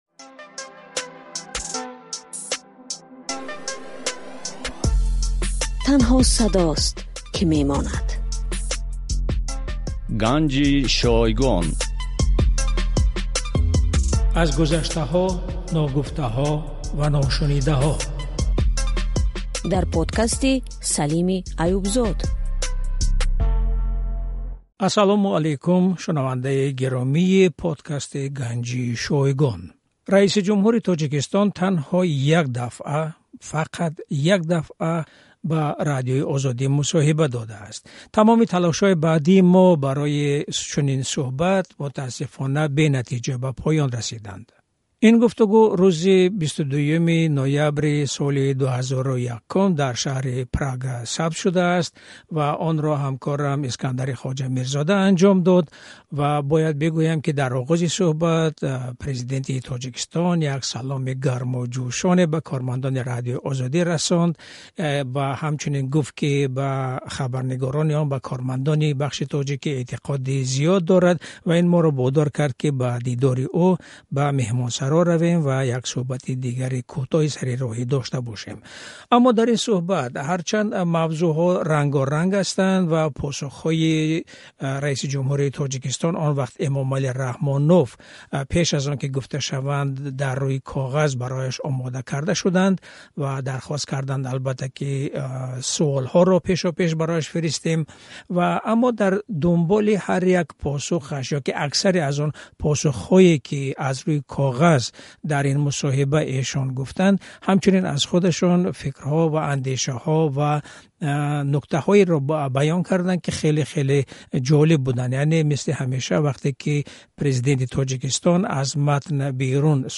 Дар тамоми тӯли фаъолияташ раисиҷумҳури Тоҷикистон Эмомалӣ Раҳмон танҳо як бор бо Радиои Озодӣ мусоҳиба кардааст. Рӯзи 22-юми ноябри соли 2001, вақте барои иштирок дар нишасти ҳамкориҳо бо НАТО ба Прага сафар карда буд.